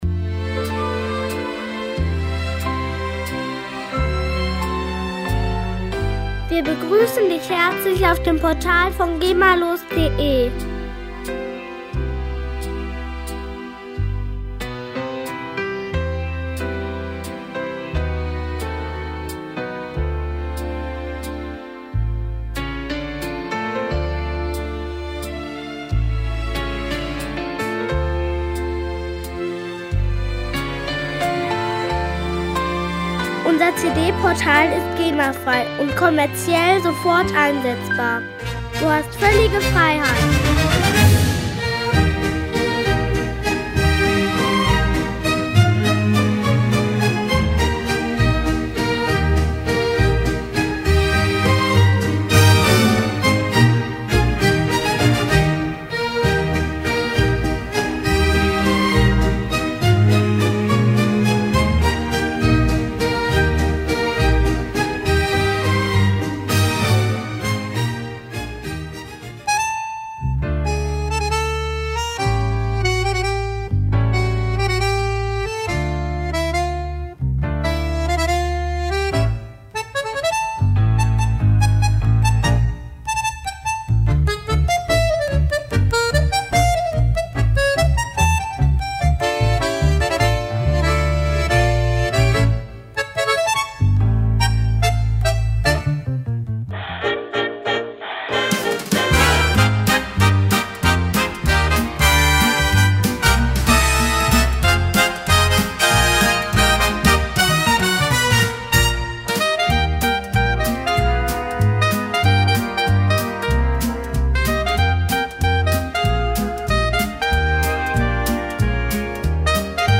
Musikstil: Ballhausmusik